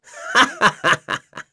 Crow-Vox_Happy2.wav